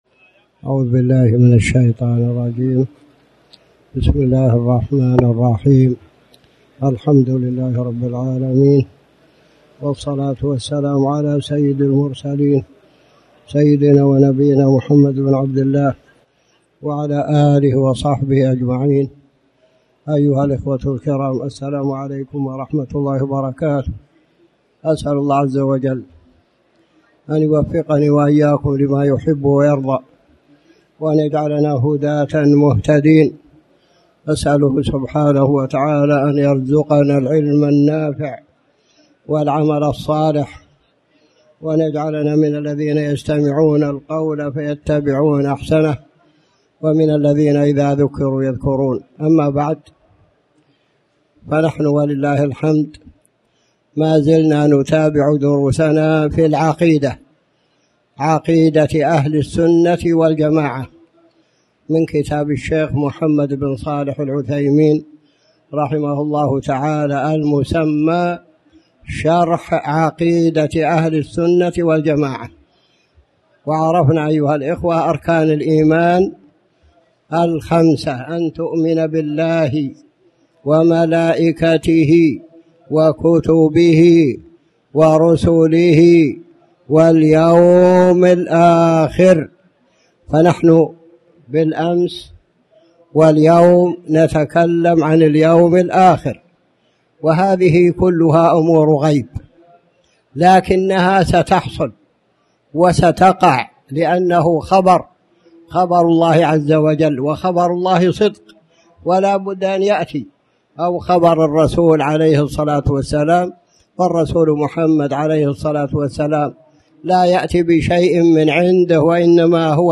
تاريخ النشر ٢ ربيع الأول ١٤٣٩ هـ المكان: المسجد الحرام الشيخ